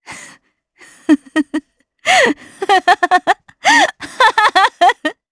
Xerah-Vox_Happy1_Madness_jp.wav